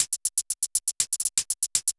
Index of /musicradar/ultimate-hihat-samples/120bpm
UHH_ElectroHatB_120-01.wav